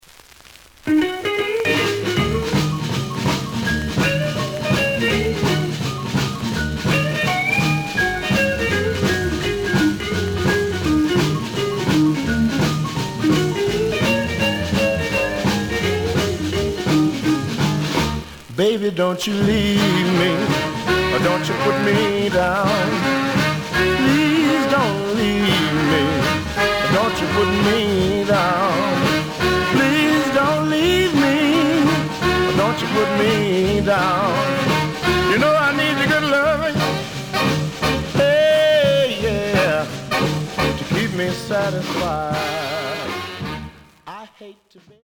試聴は実際のレコードから録音しています。
The audio sample is recorded from the actual item.
●Genre: Blues